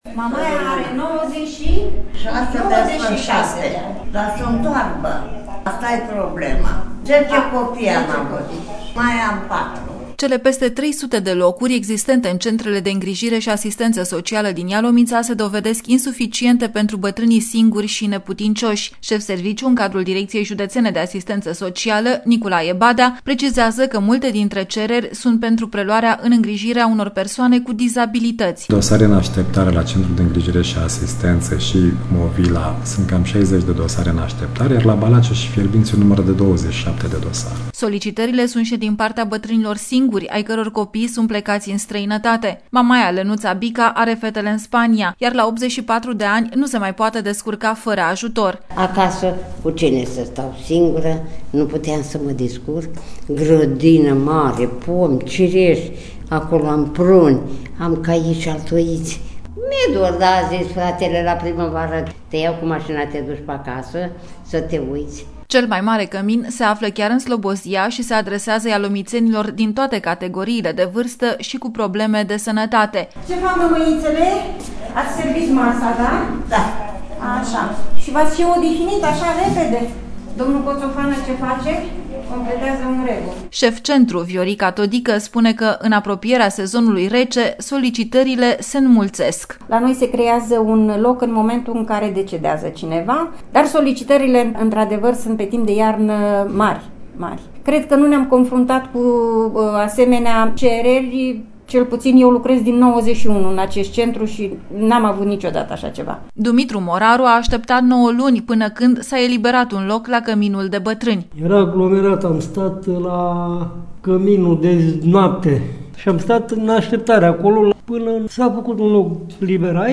reportaj-batrani.mp3